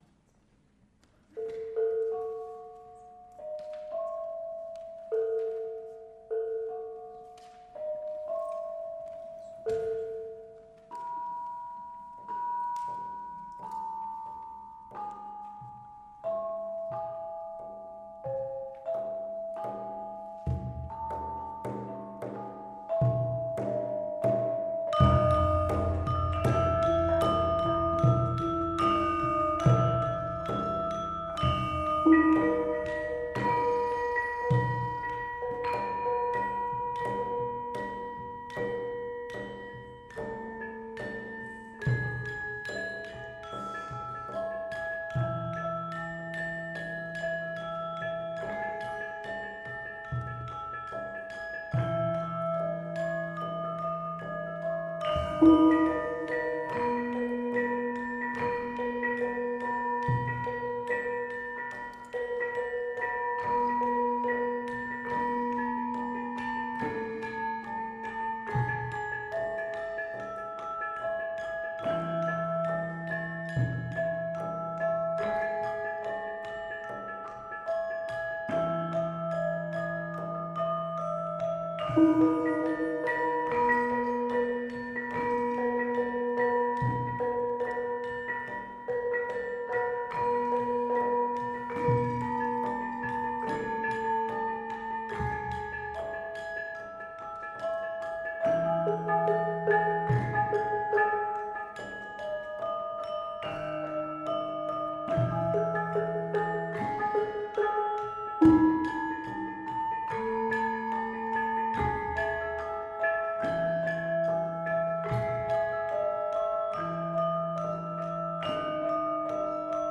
The subtle and not-so-subtle melodic and rhythmic twists and turns you hear in this piece (especially the second section, which accelerates on every repetition) are unusual, even perhaps for gendhing bonang . This piece focuses on notes 1-2-4-5-6 of the seven-note pelog scale, with the notes 3 and 7 used here to occasional, surprising effect.
01_gendhing_bonang_glendheng_pelog_lima.mp3